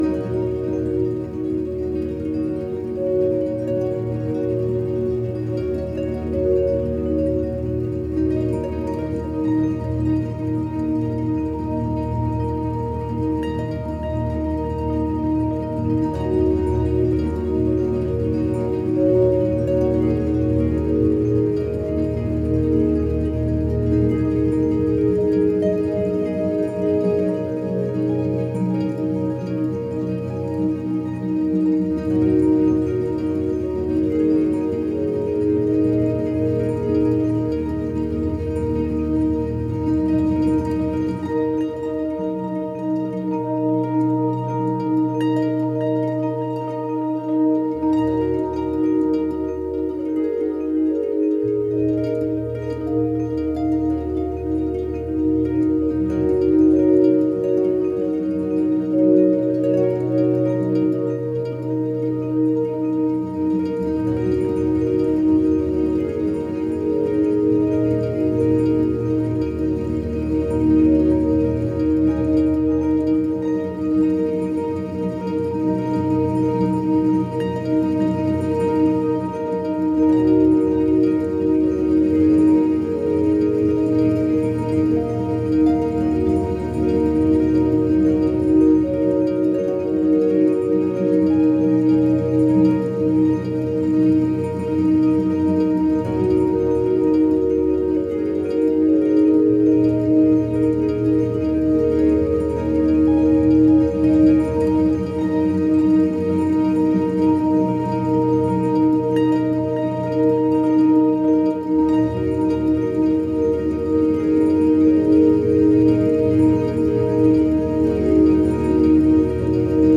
a harmonious blend of ambient synths and gentle guitar